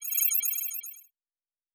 Sci-Fi Sounds / Interface